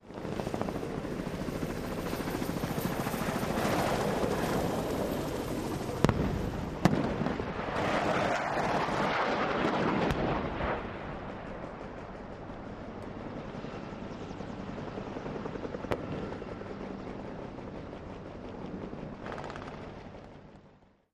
Battle simulation with rapid fire weapons and jet and helicopter flybys. Weapons, Gunfire Bombs, War Battle, Military